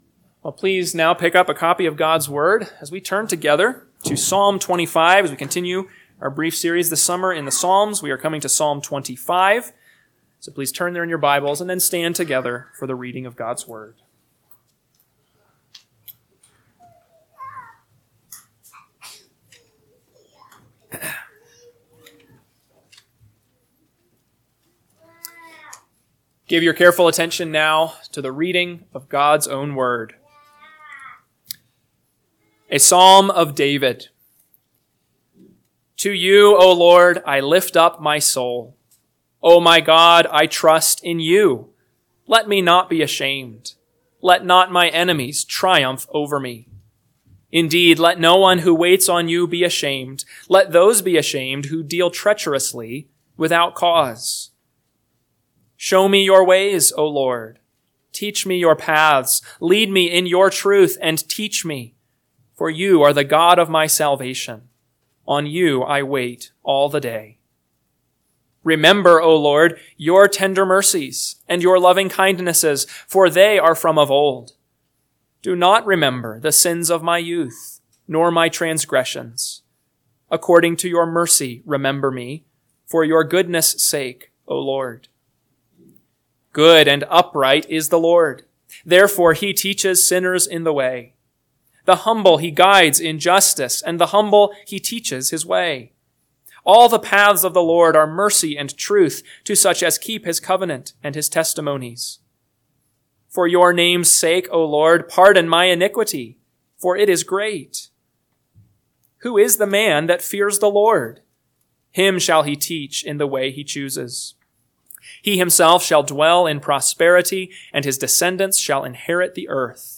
AM Sermon – 7/6/2025 – Psalm 25 – Northwoods Sermons